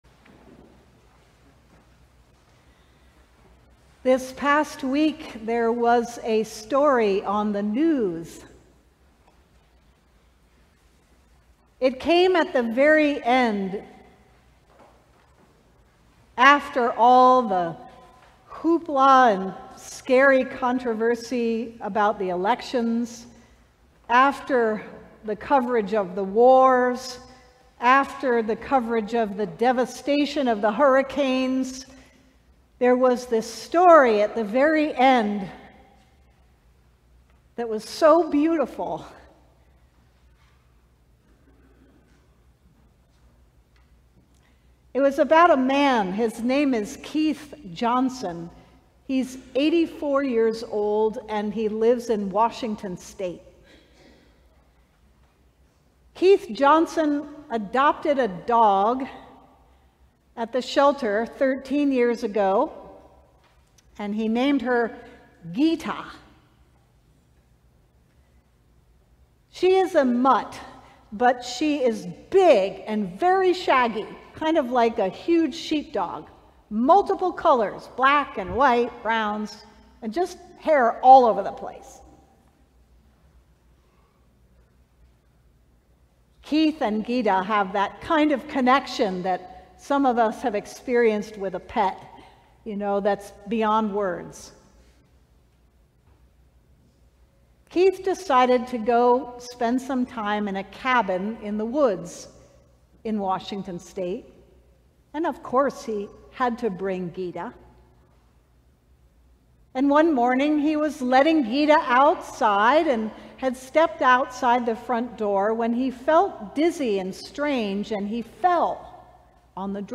Sermon: The shaggy dog
The Twenty-Second Sunday after Pentecost, October 20, 2024